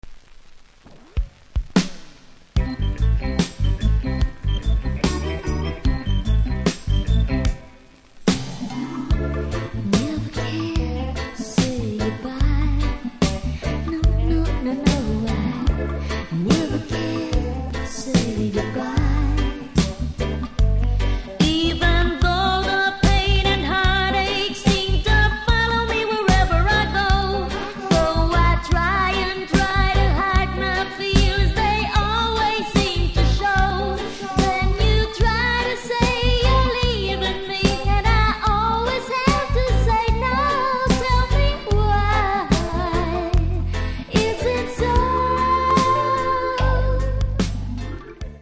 両面良いのですがセンターずれあり音に影響あります